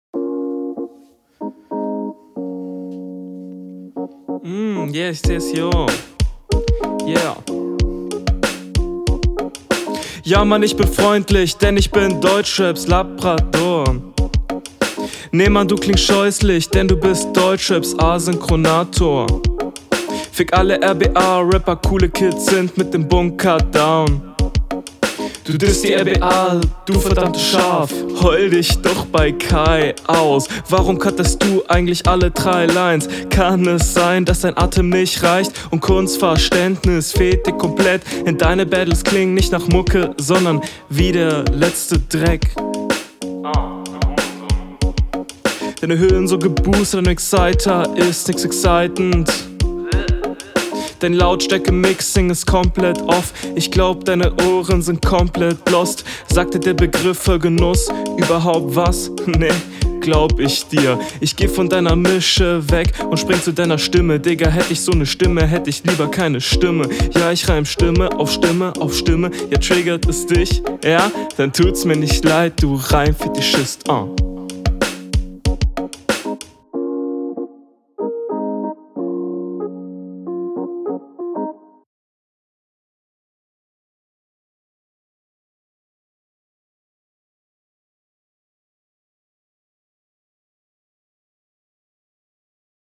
N bisschen abwechslung bei den Beats ist nice für das Battle.